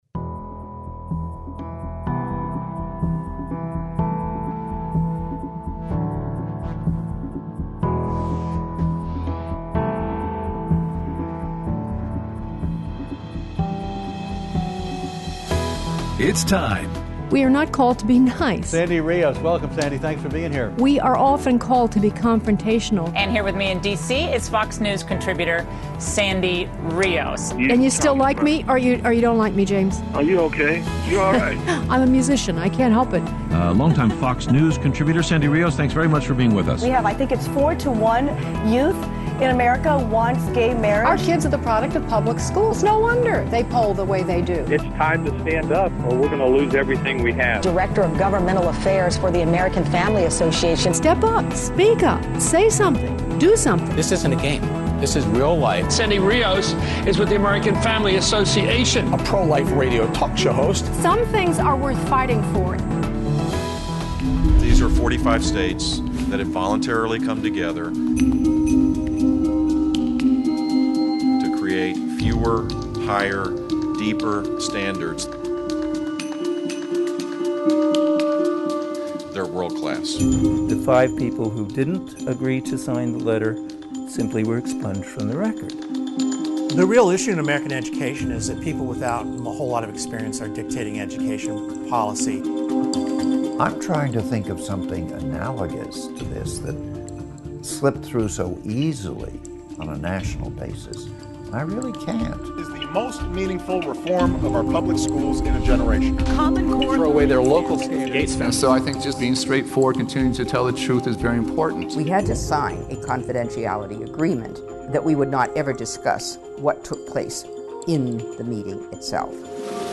They're Your Kids - Interview with Sam Sorbo